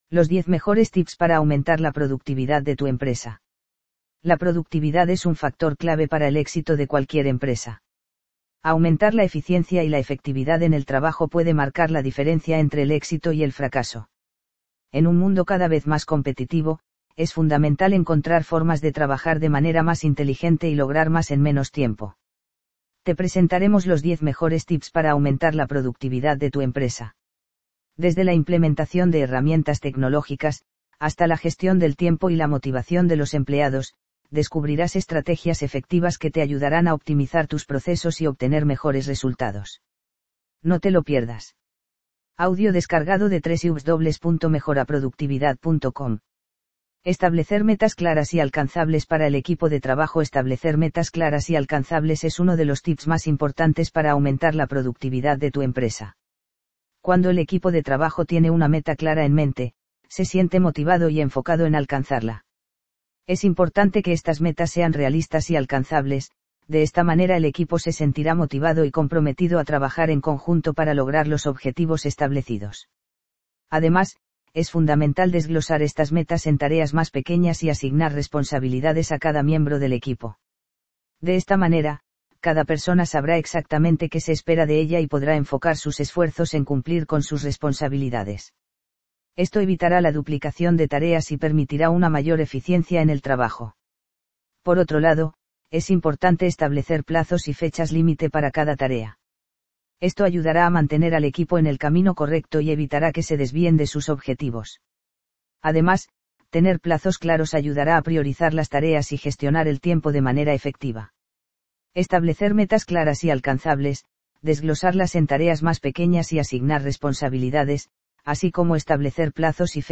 Descarga este artículo en formato de audio y disfruta de la información en cualquier momento y lugar.